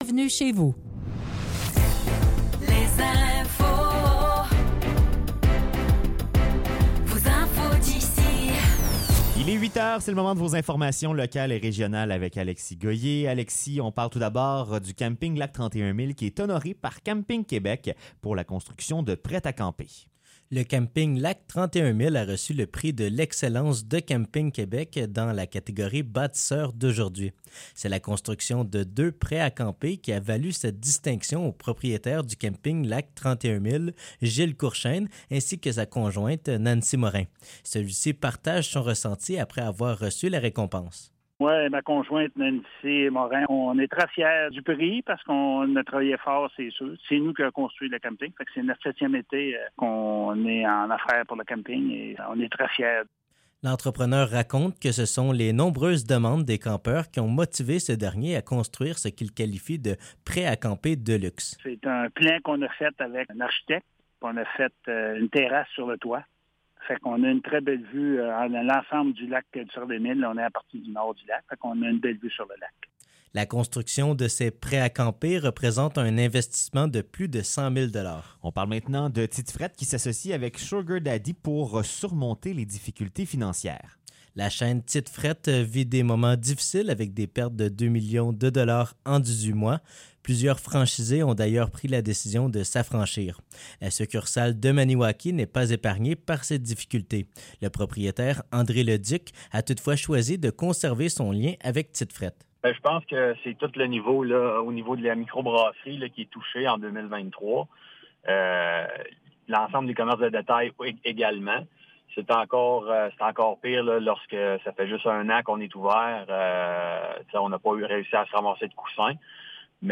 Nouvelles locales - 27 décembre 2023 - 8 h